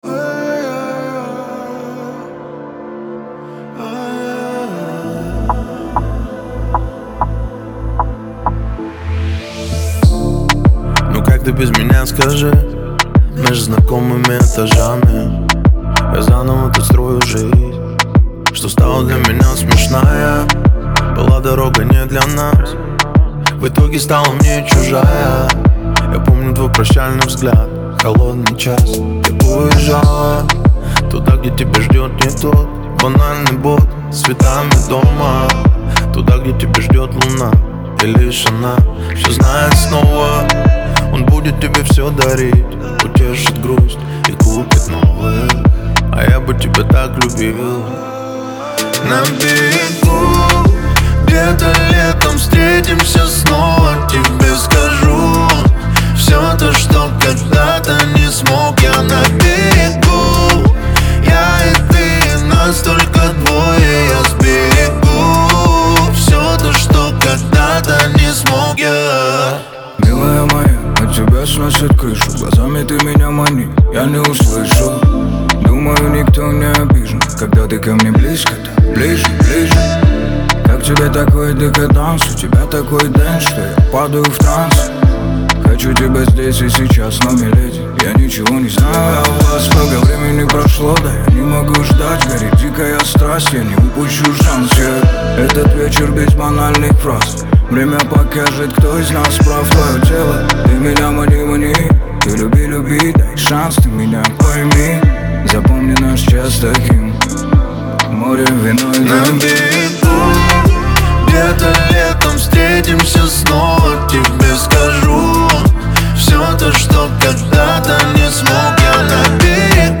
это мелодичная композиция в жанре поп